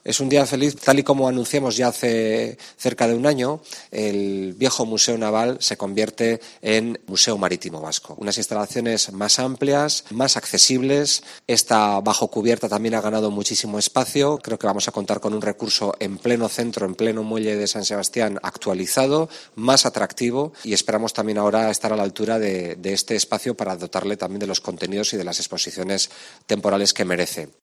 Denis Itxaso, diputado de Cultura.